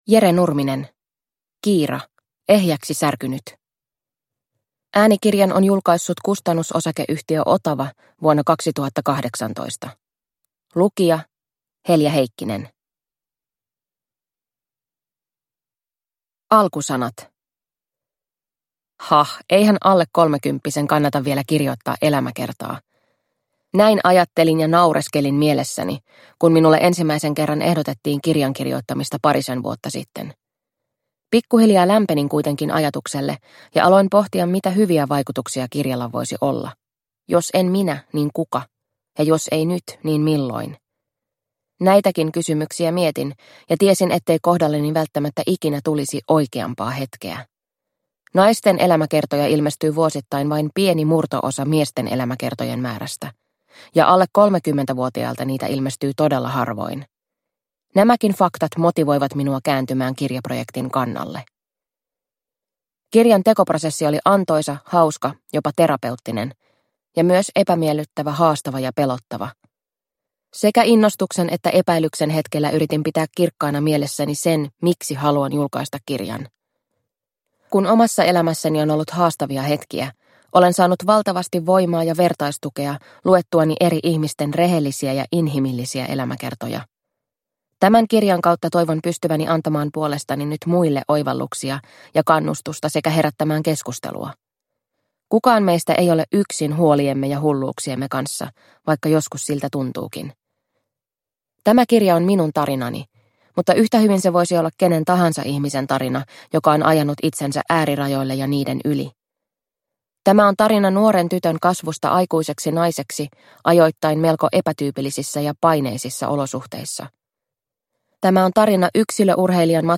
Kiira – Ljudbok – Laddas ner